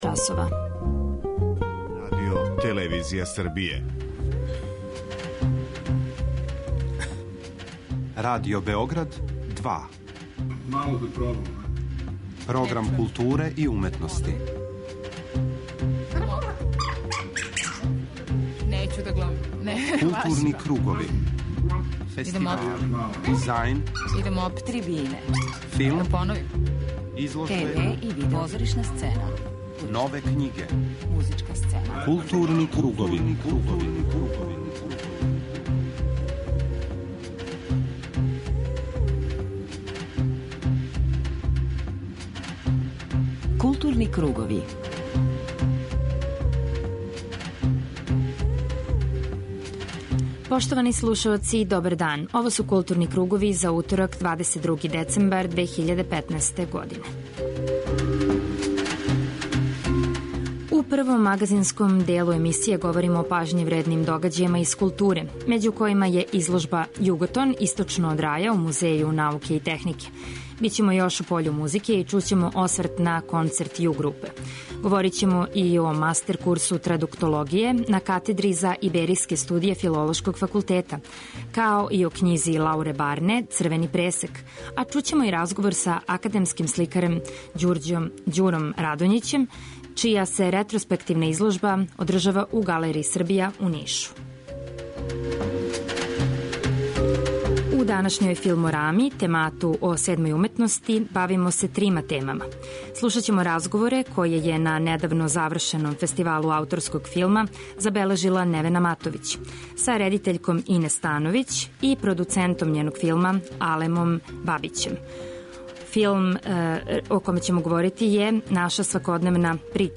У данашњој емисији чућете део атмосфере на Најкраћем дану .